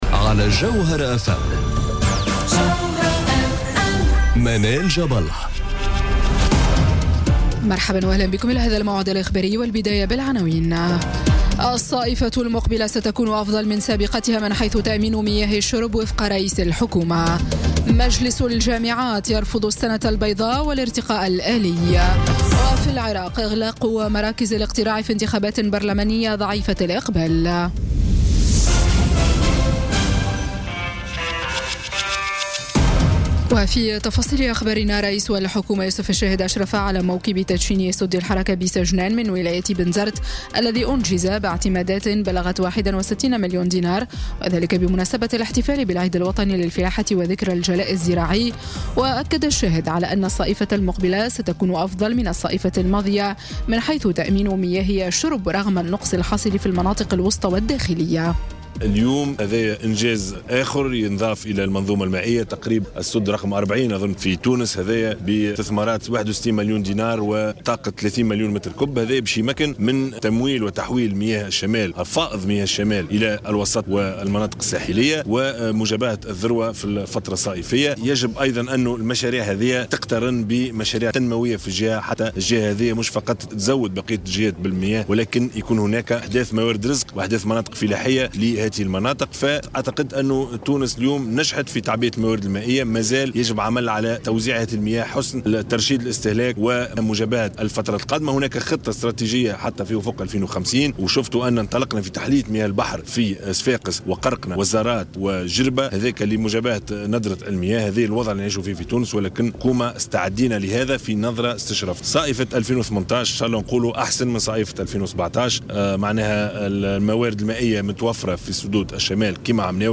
نشرة أخبار السابعة مساء ليوم السبت 12 ماي 2018